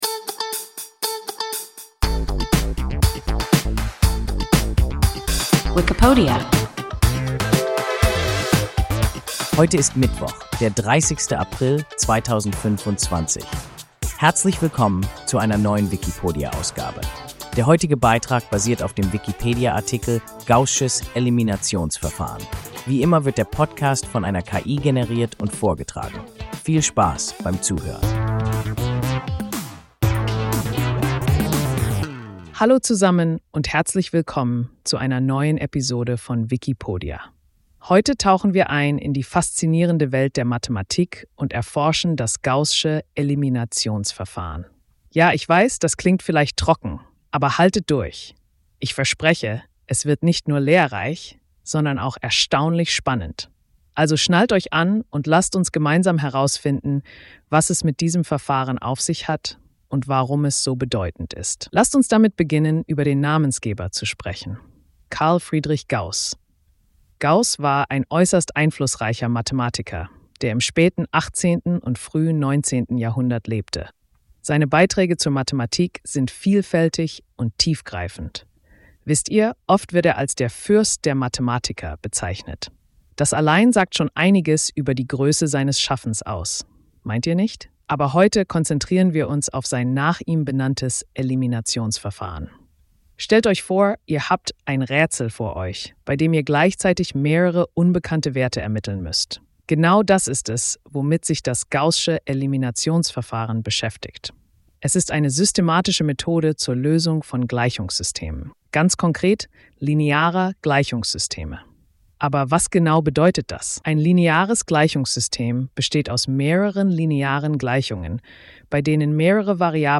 Gaußsches Eliminationsverfahren – WIKIPODIA – ein KI Podcast